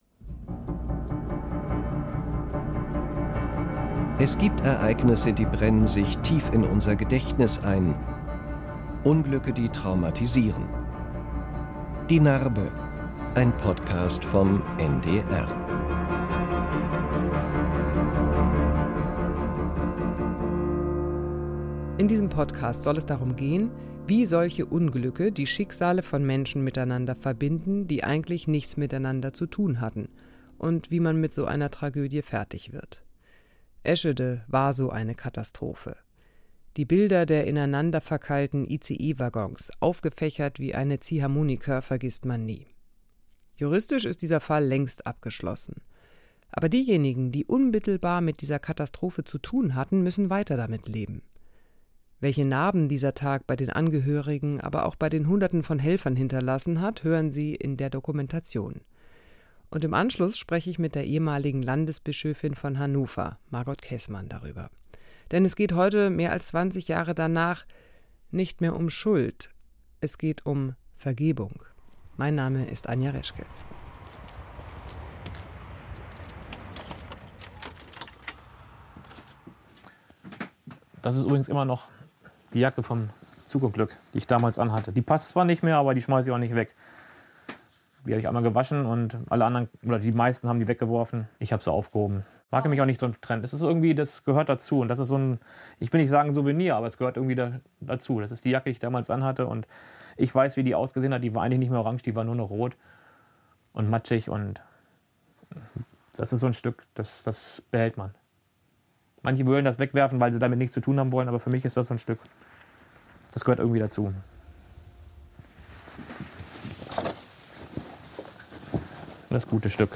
Anja Reschke spricht nach dem Film mit der Ex-EKD-Ratsvorsitzenden Margot Käßmann.